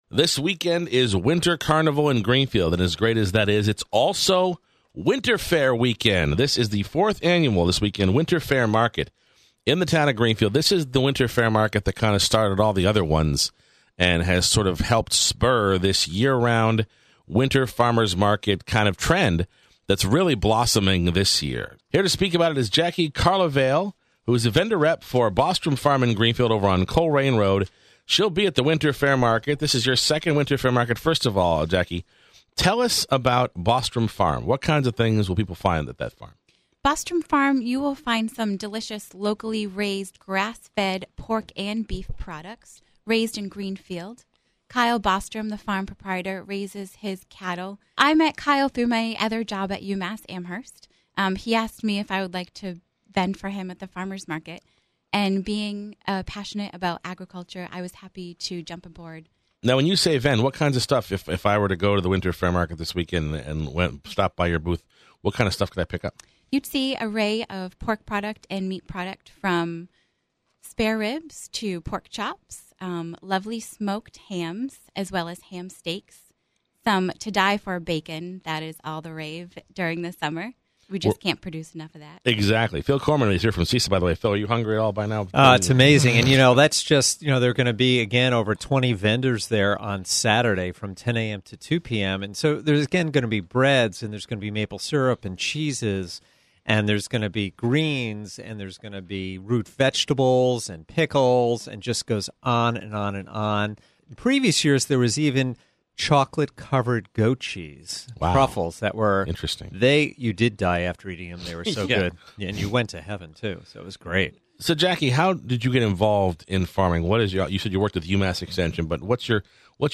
Interview: Greenfield Winter Fare